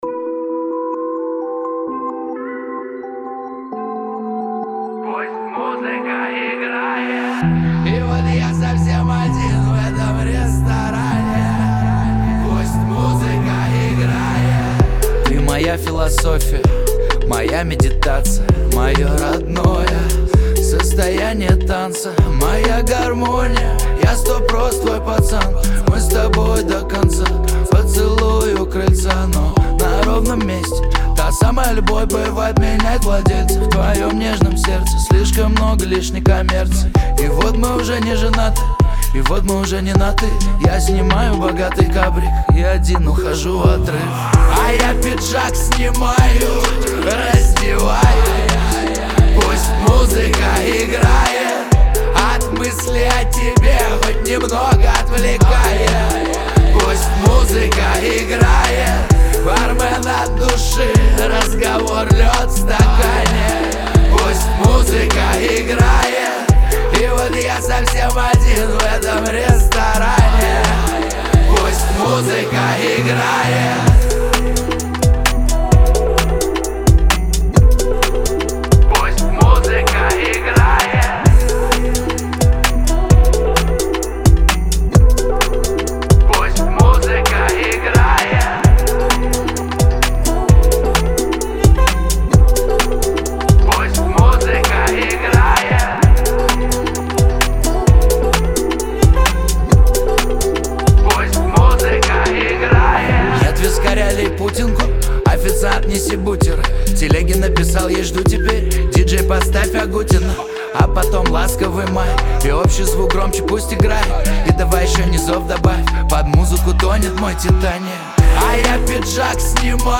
Лирика
ХАУС-РЭП